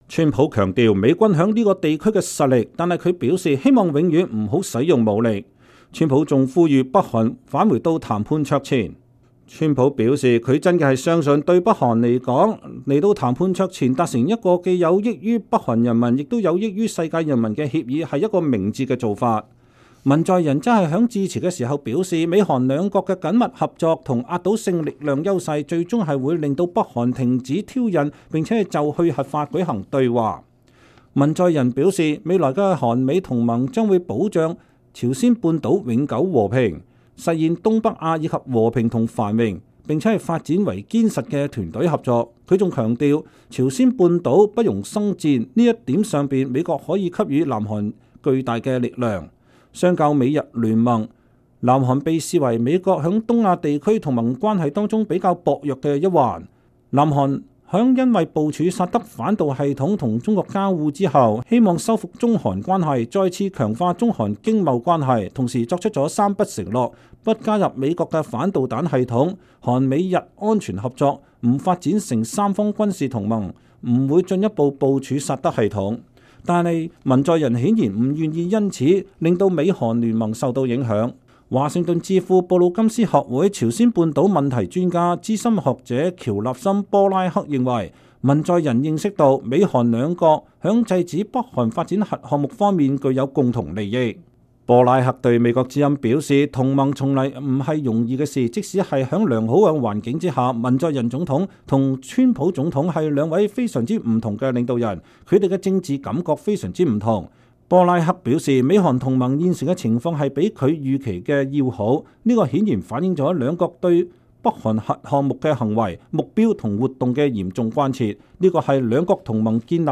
2017年11月8日，美國總統川普在韓國國會發表演講。
在首爾火車站裡，一些等車的市民正在觀看川普講話。